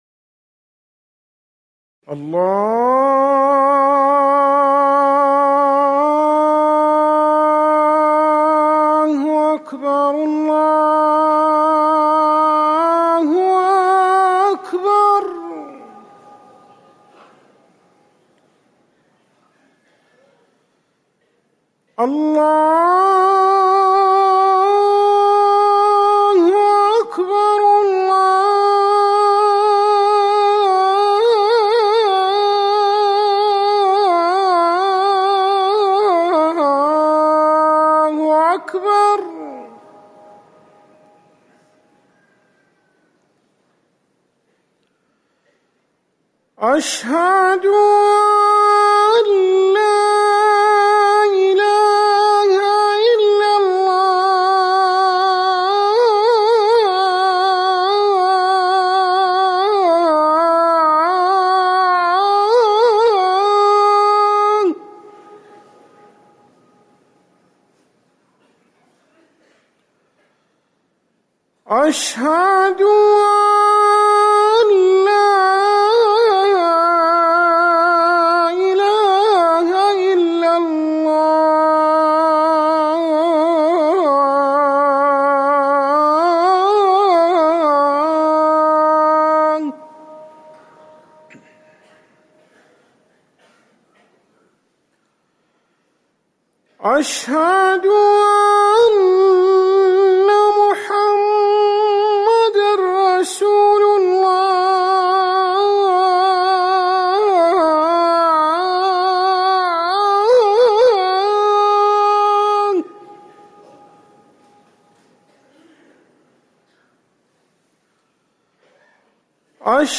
أذان الفجر الأول
تاريخ النشر ١٥ محرم ١٤٤١ هـ المكان: المسجد النبوي الشيخ